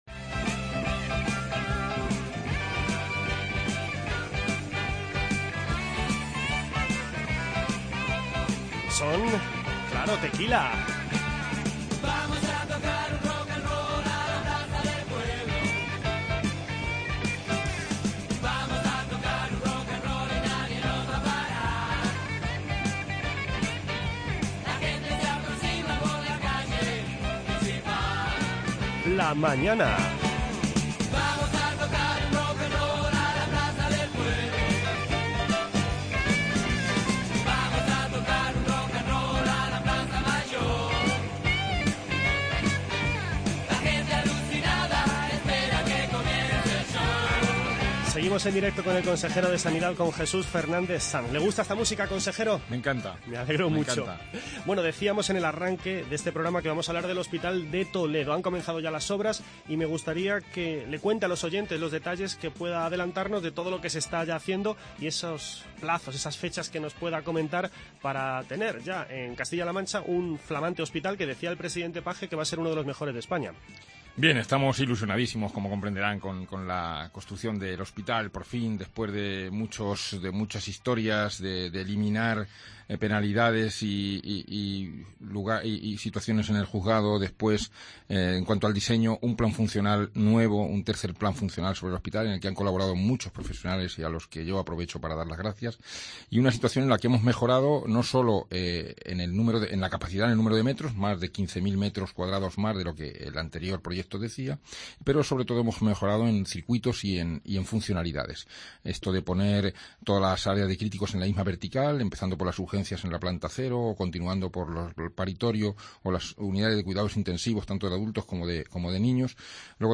El consejero de Sanidad es el protagonista en los micrófonos de la Cadena COPE.